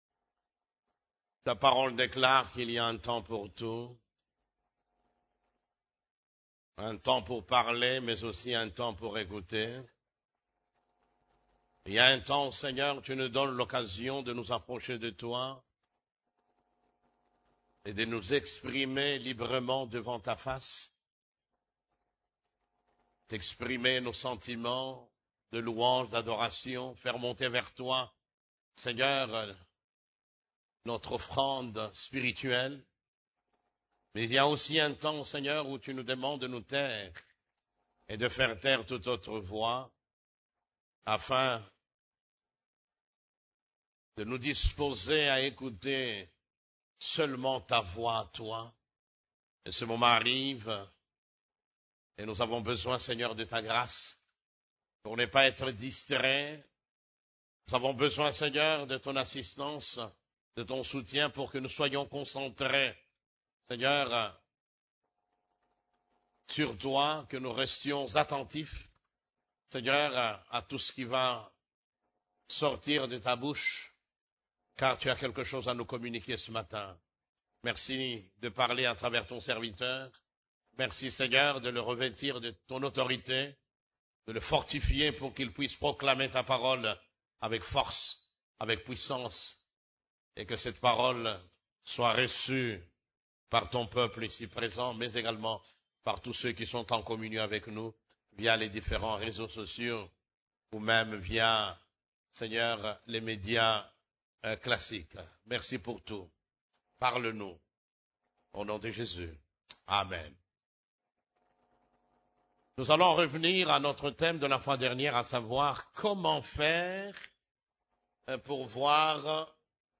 CEF la Borne, Culte du Dimanche, Comment voir l'invisible ? (2)